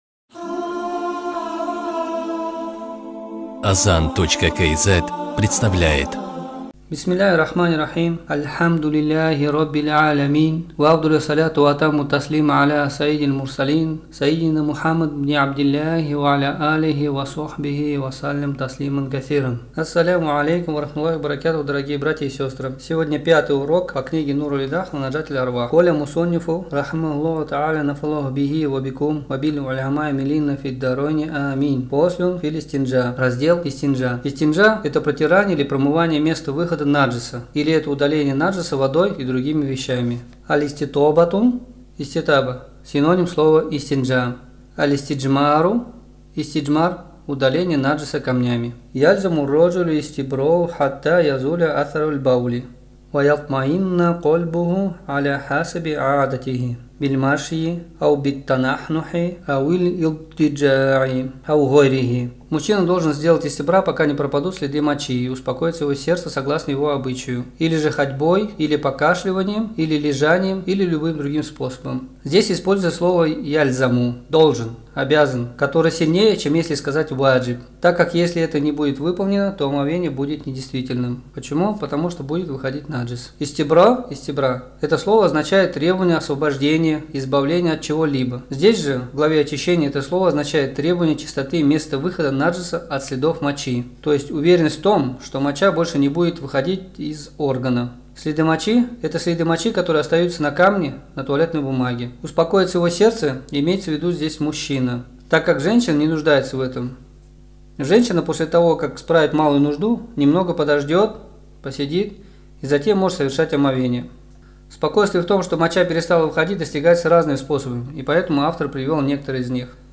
5 урок: Истинджа